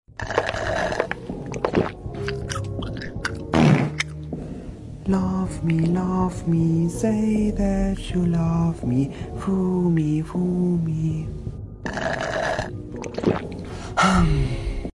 The Fart Gets Me Everytime Sound Effects Free Download